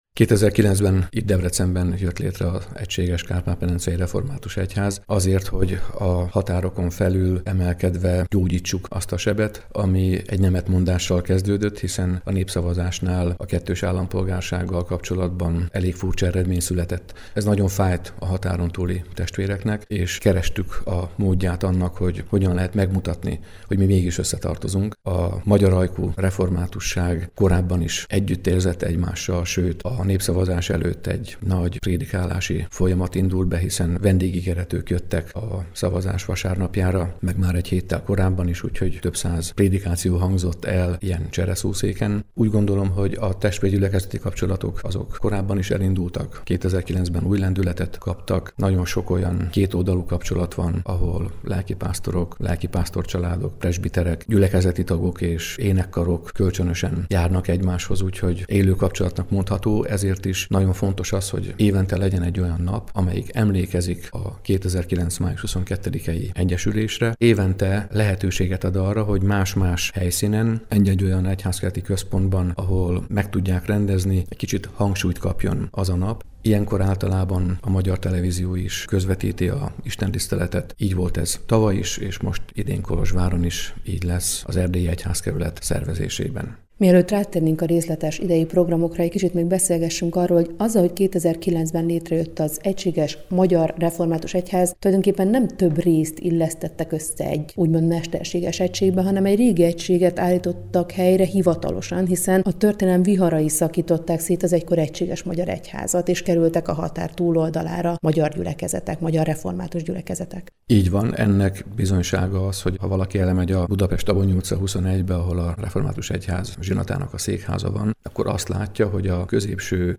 Ennek a történelmi újraegyesülésnek a tiszteletére május 22-e a Református Egység Napja. Erről beszélgetett az Európa Rádióban az ünnep előtt